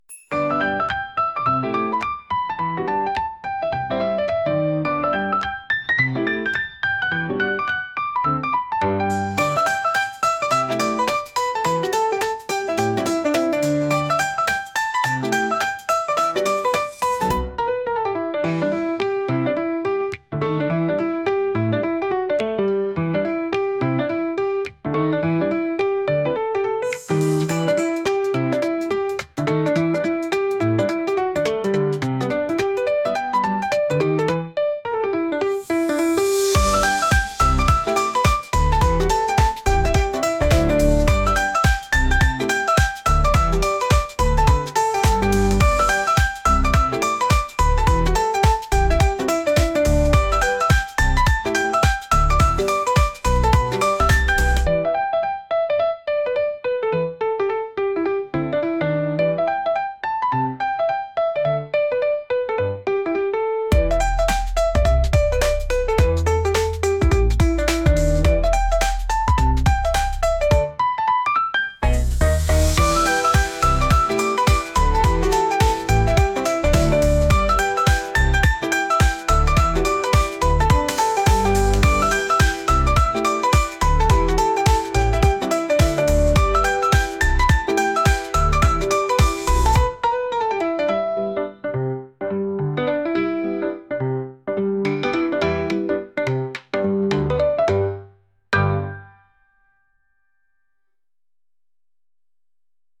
探偵が調査を開始するようなピアノ曲です。